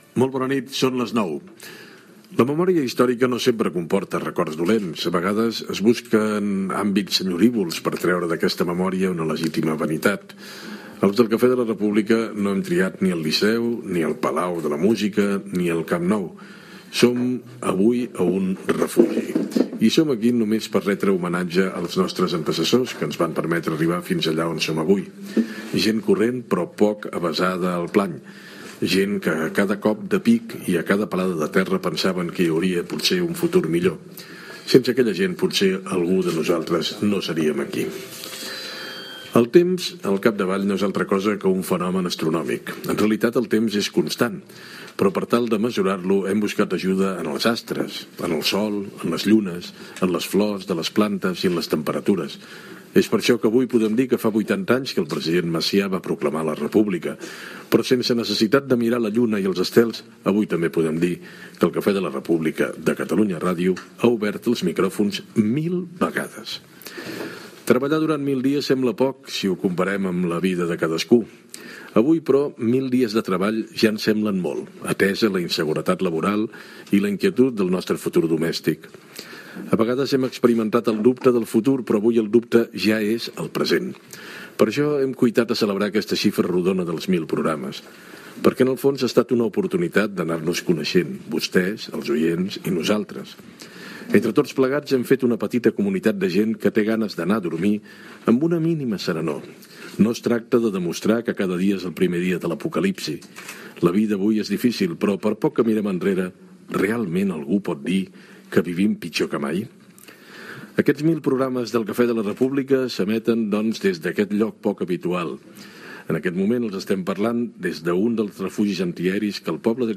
Descripció del refugi antiaeri del Poble Sec on es fa el programa i reportatge amb una persona que va haver de refugiar-s'hi durant la Guerra civil espanyola. Gènere radiofònic Informatiu
El programa 1.000 del Cafè de la República es va fer al Refugi Antiaeri 307, ubicat al barri del Poble Sec, gestionat pel Museu d'Història de Barcelona.